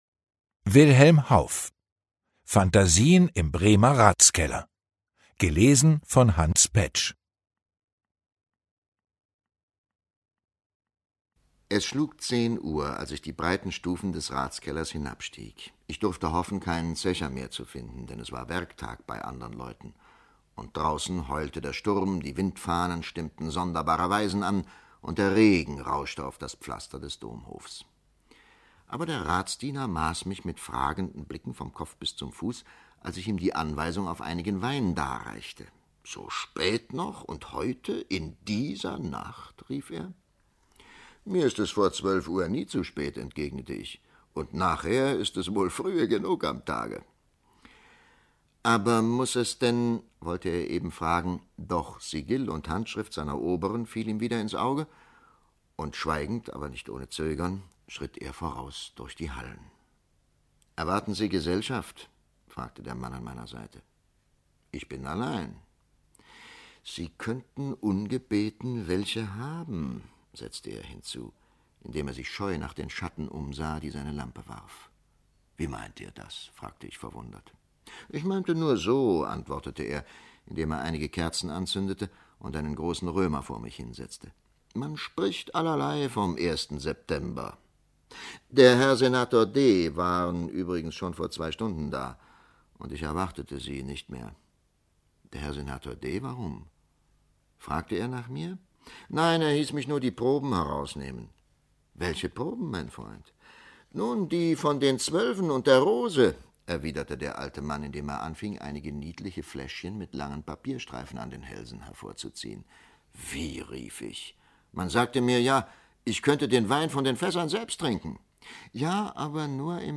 Hans Paetsch (Sprecher)
Gekürzte Lesung